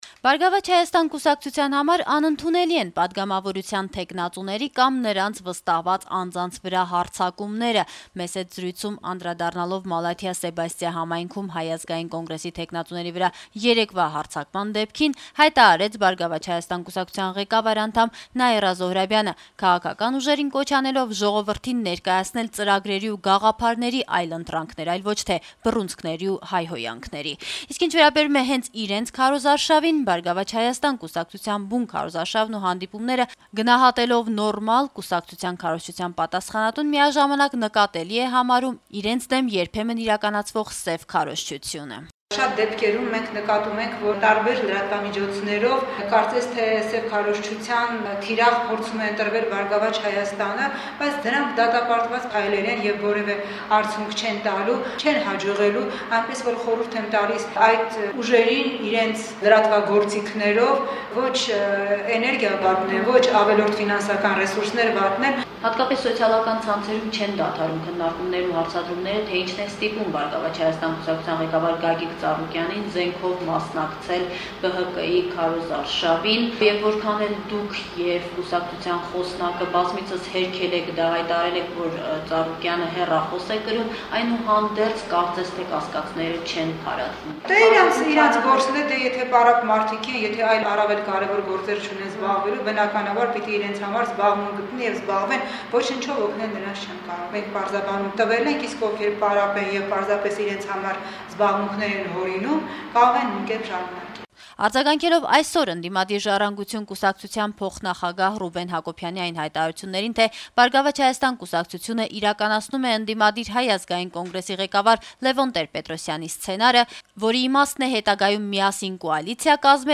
«Բարգավաճ Հայաստան» կուսակցության համար անընդունելի են պատգամավորության թեկնածուների, նրանց վստահված անձանց վրա հարձակումները», - «Ազատություն» ռադիոկայանի հետ զրույցում անդրադառնալով Մալաթիա-Սեբաստիա համայնքում Հայ ազգային կոնգրեսի թեկնածուների վրա կիրակի օրվա հարձակման դեպքին՝ հայտարարեց ԲՀԿ ղեկավար անդամ Նաիրա Զոհրաբյանը: Նա քաղաքական ուժերին կոչ արեց ժողովրդին ներկայացնել «ծրագրերի ու գաղափարների այլընտրանքներ, այլ ոչ թե բռունցքների ու հայհոյանքների»: